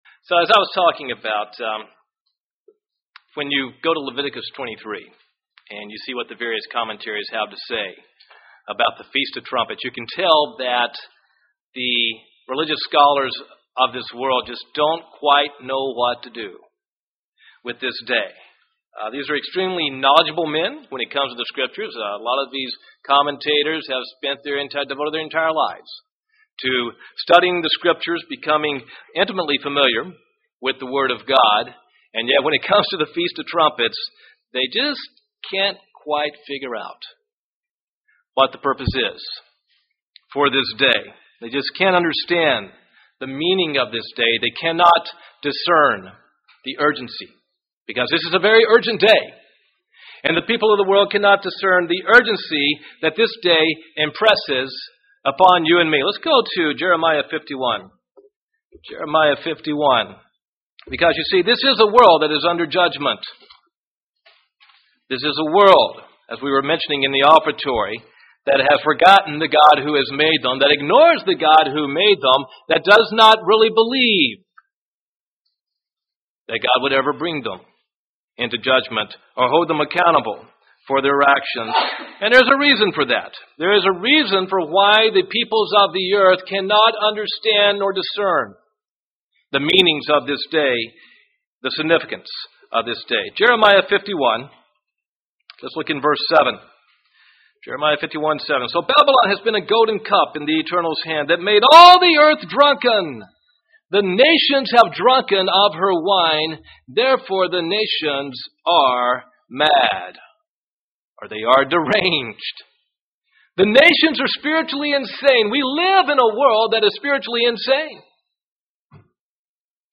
Given in Huntsville, AL
UCG Sermon Studying the bible?